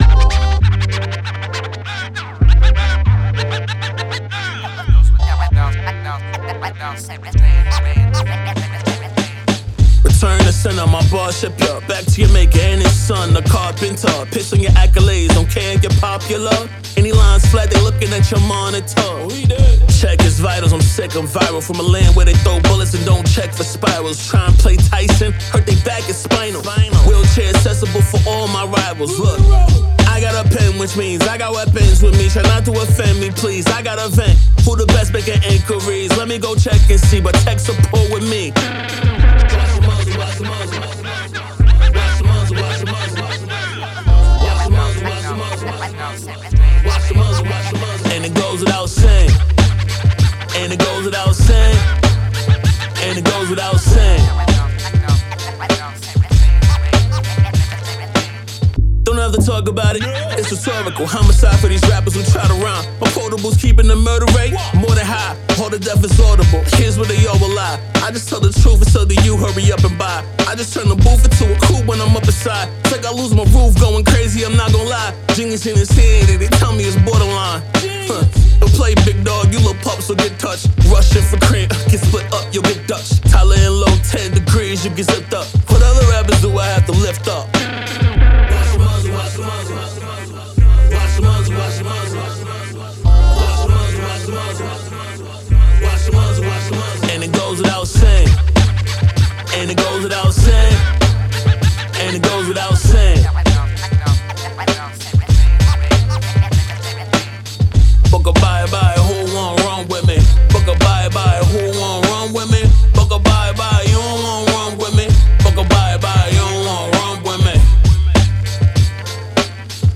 Rapper, Songwriter
Hip Hop, 90s
G minor